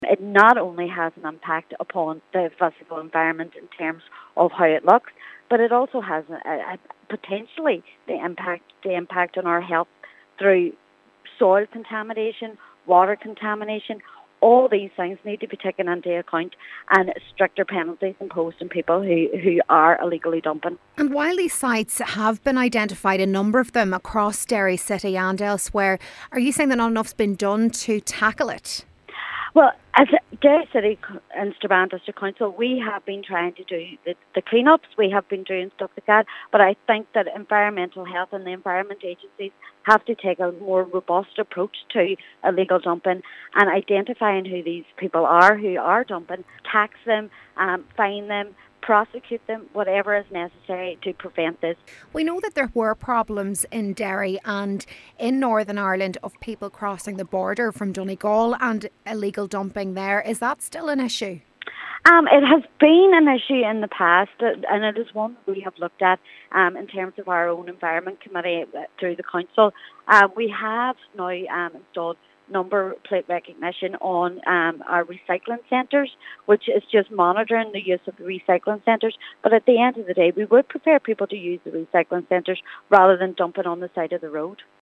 Local Cllr. Sandra Duffy says there are a number of places in and around the city that have been identified as illegal dumping sites but are still being used on a regular basis: